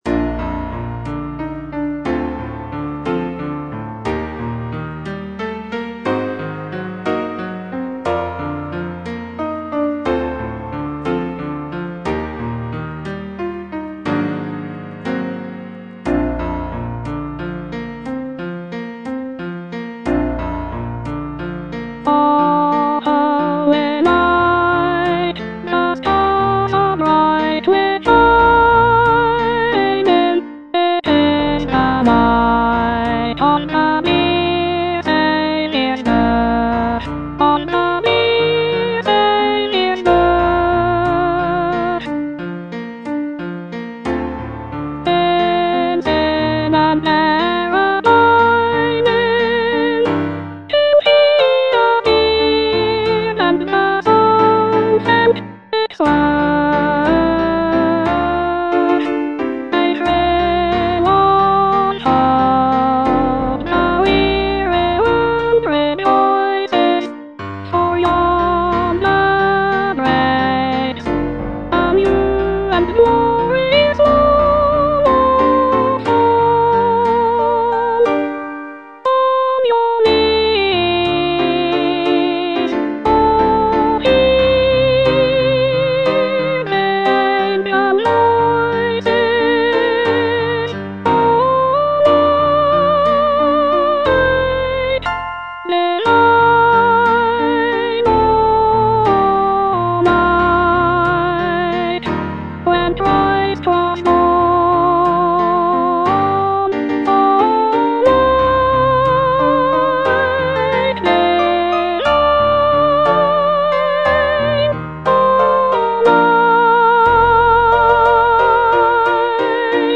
Soprano II (Voice with metronome)